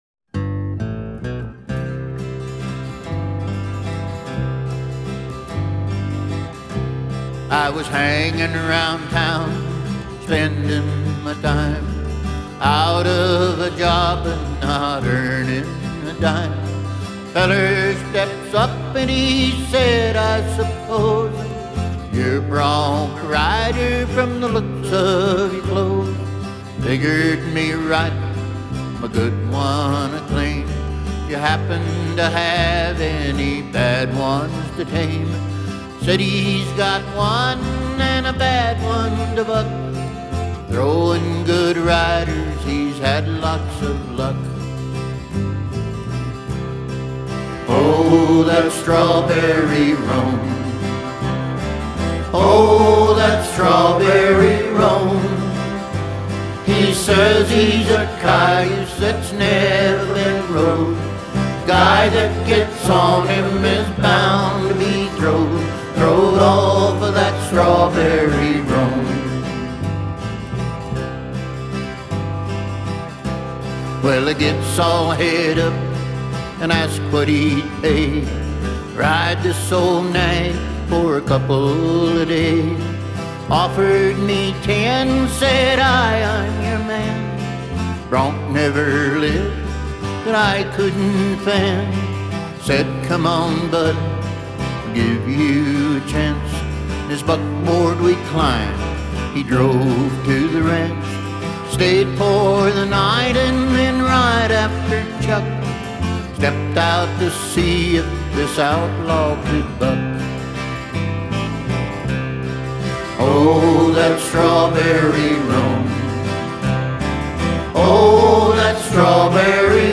Strawberry Roan Song Enjoy listening to the Strawberry Roan song performed by the Rusty Pistols Cowboy Band below.